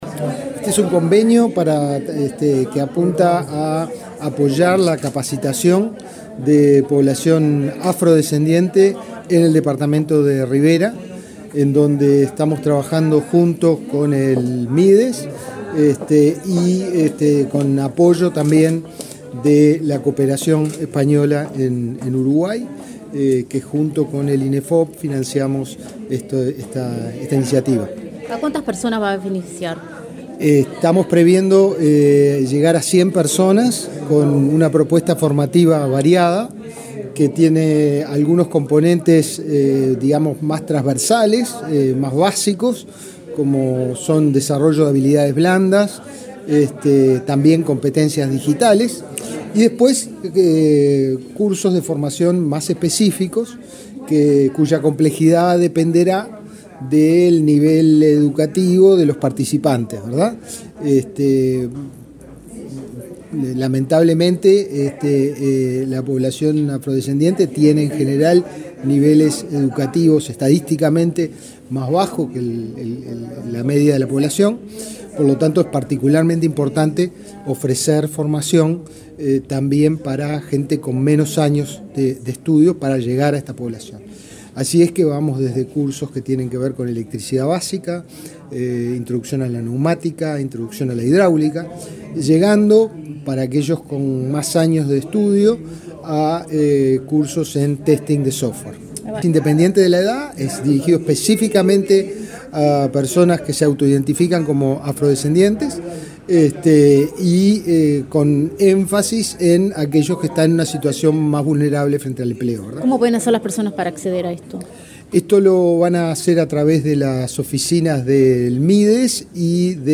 Entrevista al director del Inefop, Pablo Darscht